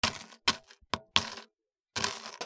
氷 突く
/ M｜他分類 / L35 ｜雪・氷
『ザ』